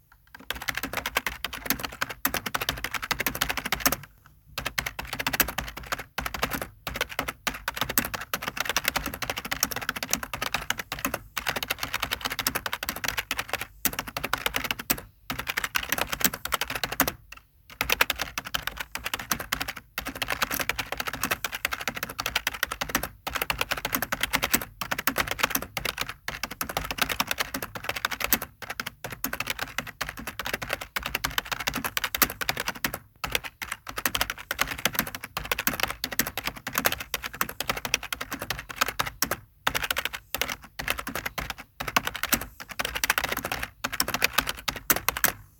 Keyboard Typing
click computer desktop keyboard lenovo type typing sound effect free sound royalty free Sound Effects